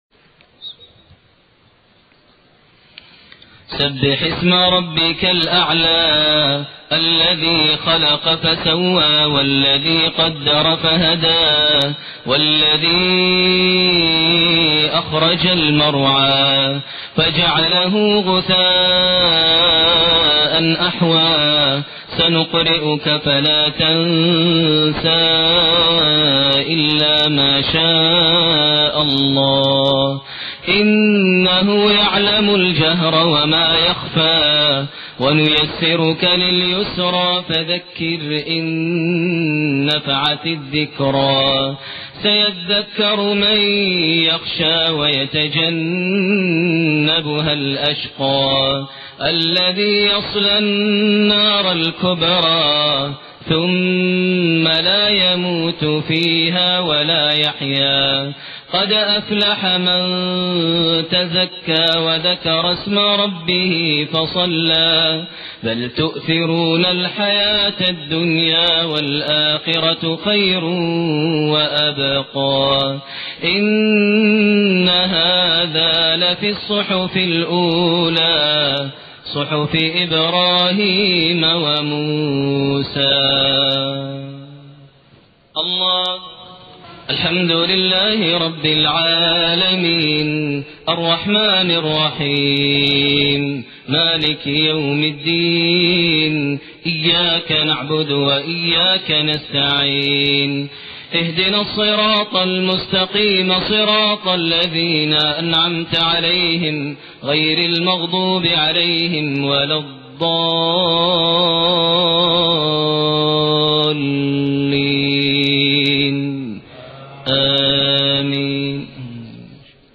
Maghrib prayer from Sura Al-A'laa and An-Nasr > 1428 H > Prayers - Maher Almuaiqly Recitations